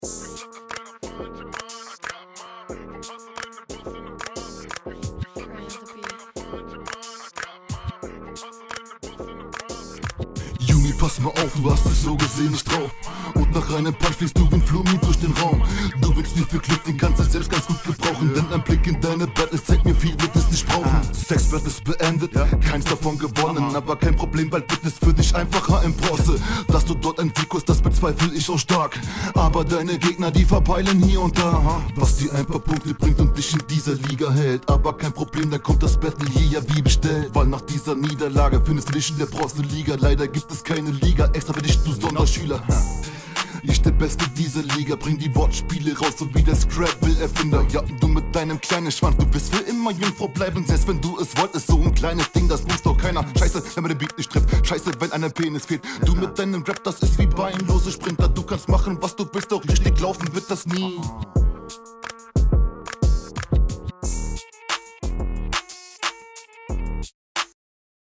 Flow zwar größtenteils sicher auf dem Takt, aber häufig eher gleich mit eher …